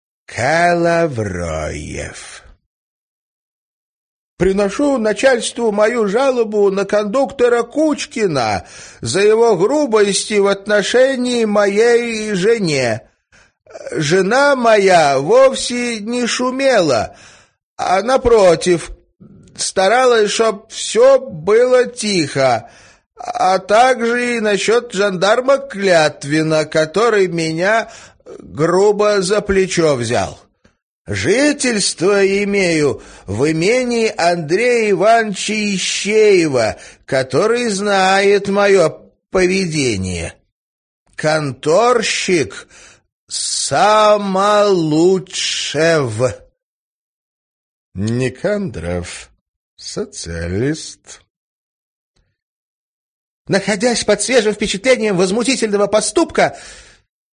Аудиокнига Жалобная книга | Библиотека аудиокниг